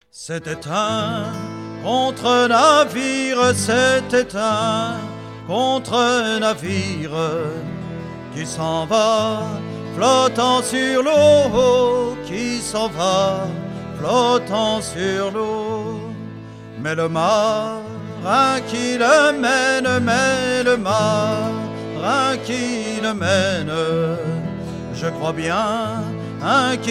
circonstance : maritimes
Pièce musicale éditée